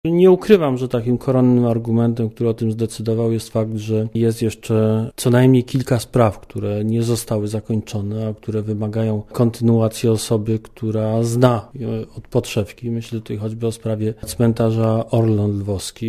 Mówi Andrzej Przewoźnik Swą kandydaturę złożył już obecny prezes IPN Leon Kieres.